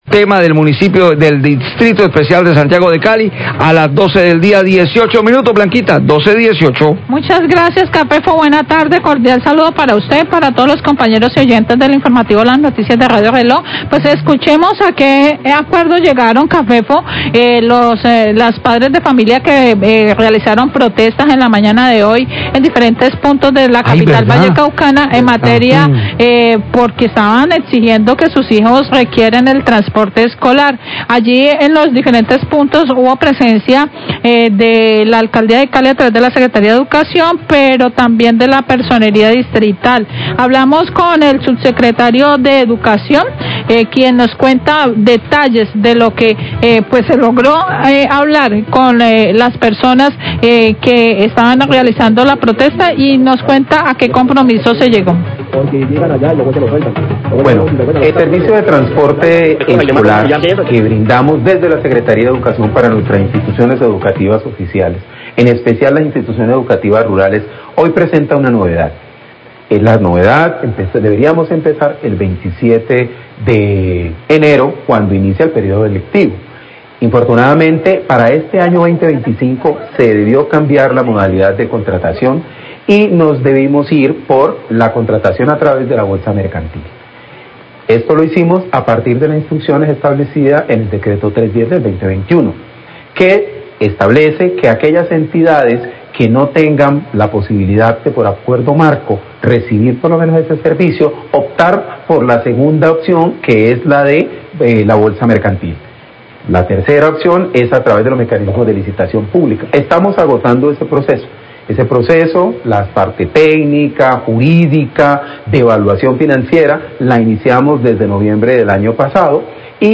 Subsecretario de Educación habla de transporte escolar, 1214pm